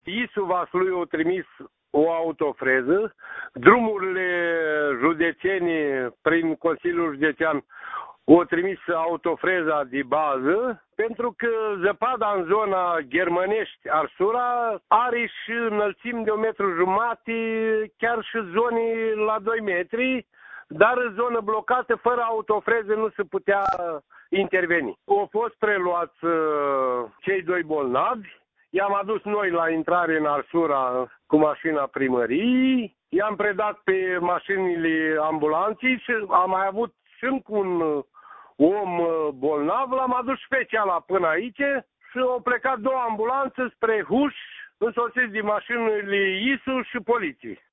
Primarul comunei, Mihai Mitrofan, a declarat, pentru Radio Iași, că pe traseul respectiv zăpada măsoară un metru și jumătate, chiar doi: